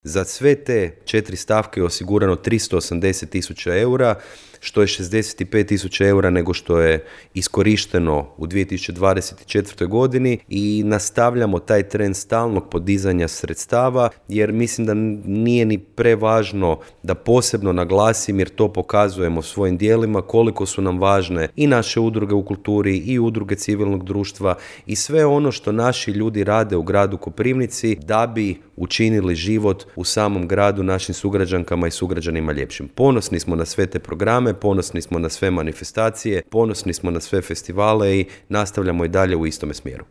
– kazao je gradonačelnik Mišel Jakšić.